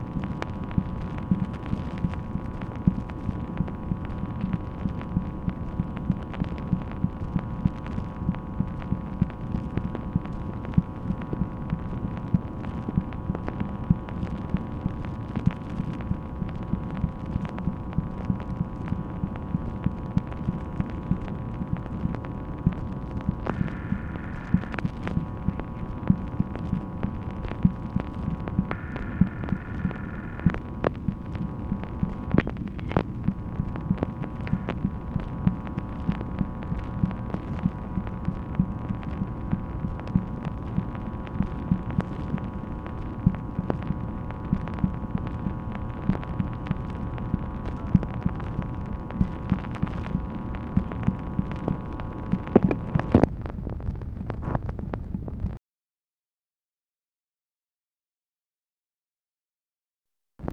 MACHINE NOISE, November 29, 1965
Secret White House Tapes | Lyndon B. Johnson Presidency